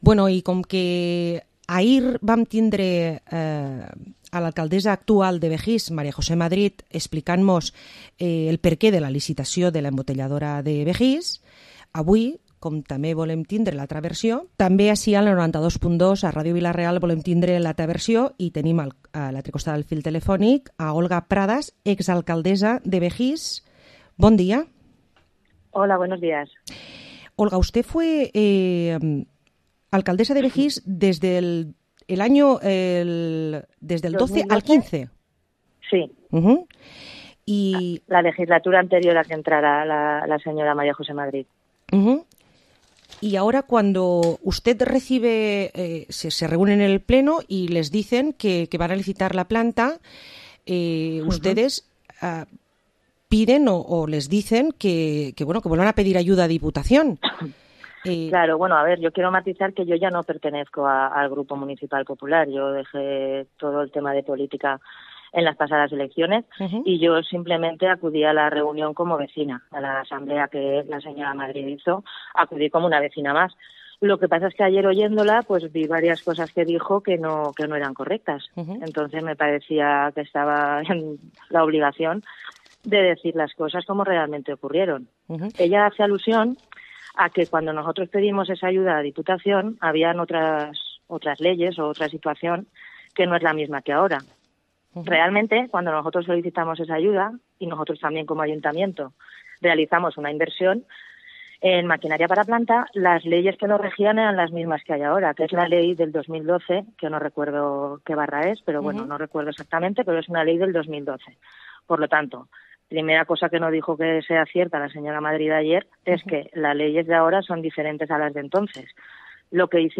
Entrevista a la ex-alcaldesa de Bejís (PP), Olga Pradas, por la situación de la embotelladora